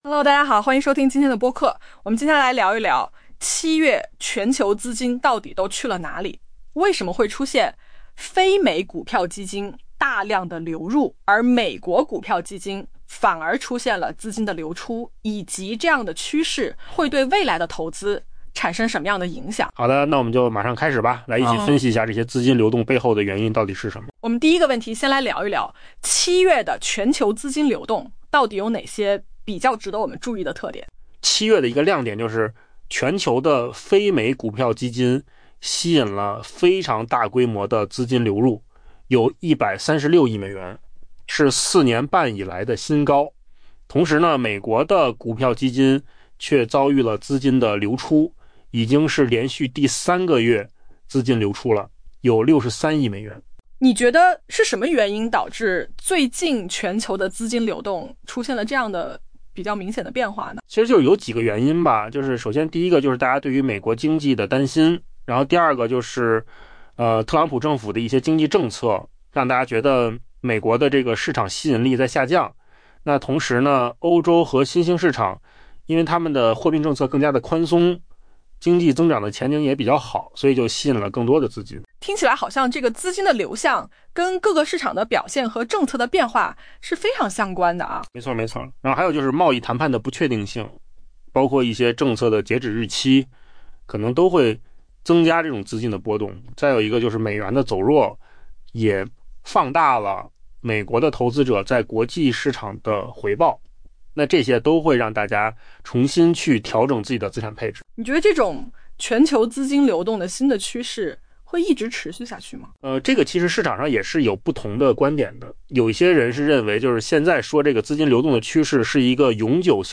音频由扣子空间生成